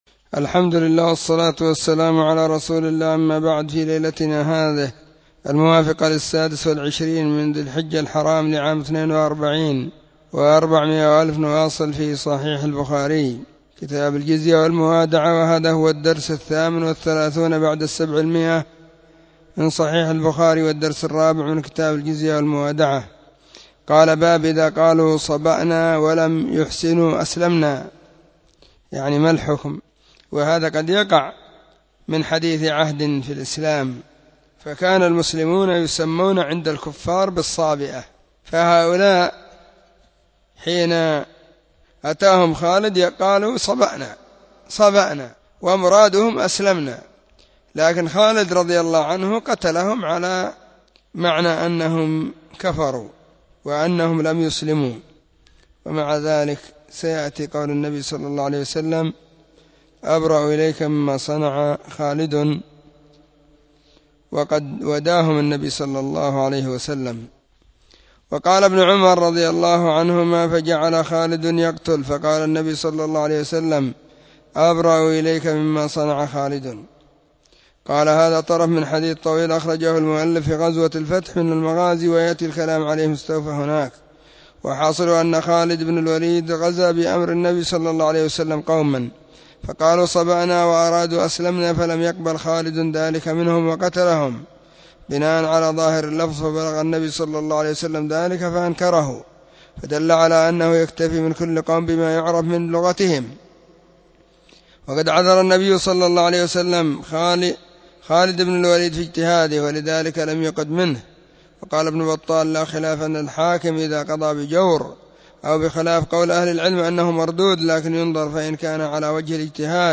🕐 [بين مغرب وعشاء – الدرس الثاني]
كتاب-الجزية-والموادعة-الدرس-4.mp3